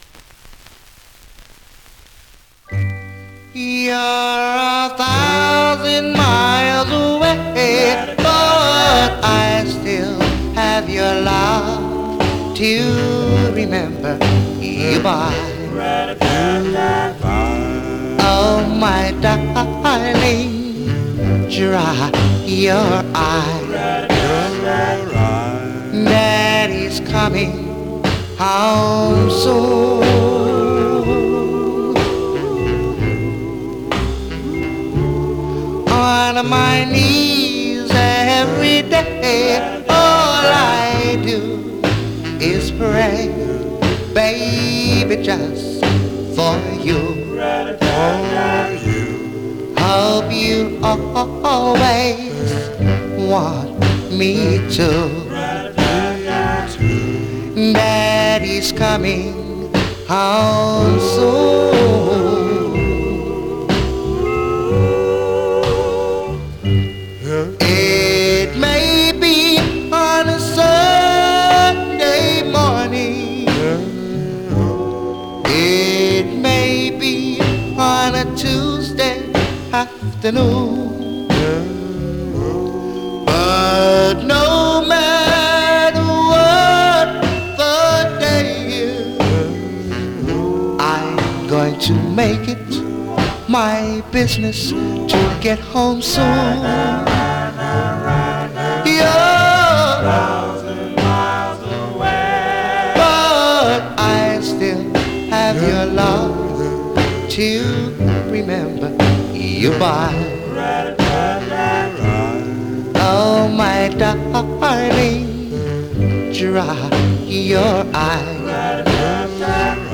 Condition Surface noise/wear Stereo/mono Mono
Male Black Groups